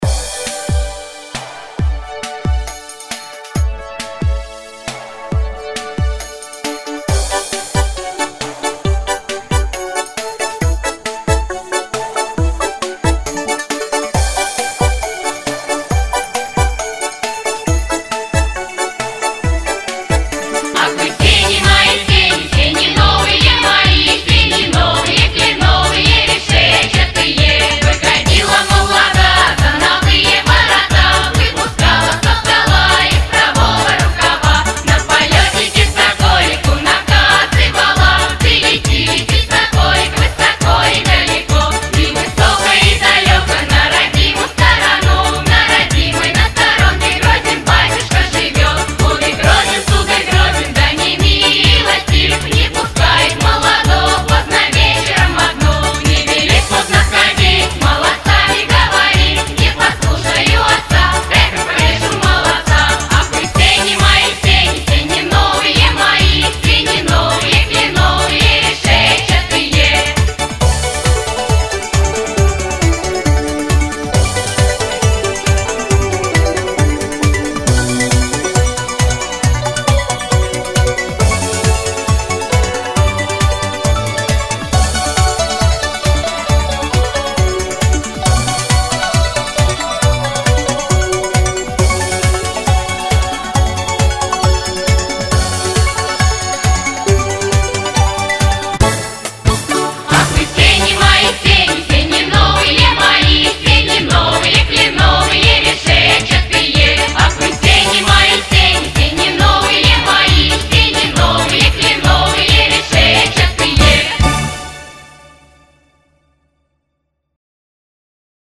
Ах вы сени мои сени Ансамбль Забава -Волгоград